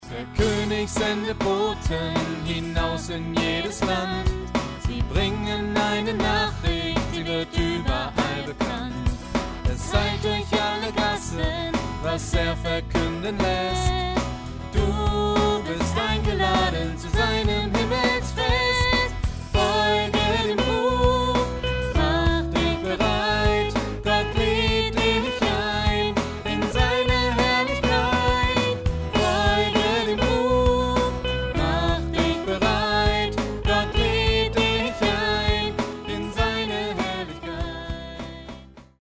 Thema: Kinderlied
Tonart: E
Taktart: 4/4
Tempo: 192 bpm
Kinderlied